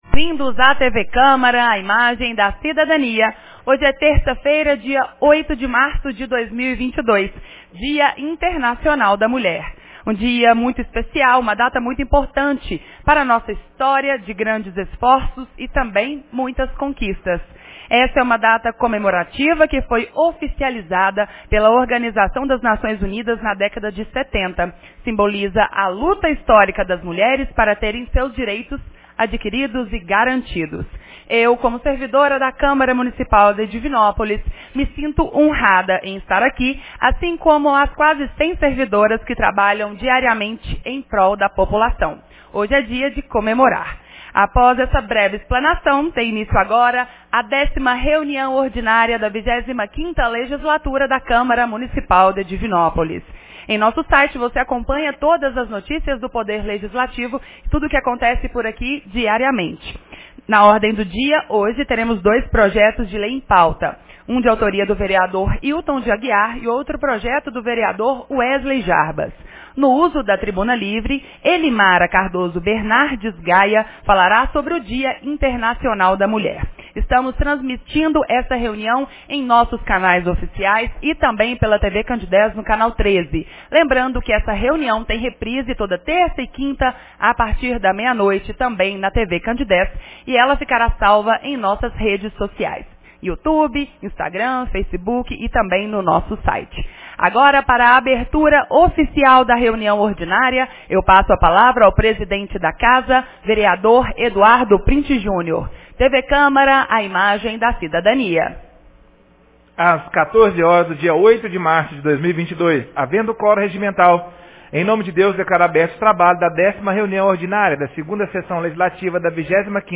10ª Reunião Ordinária 08 de março de 2022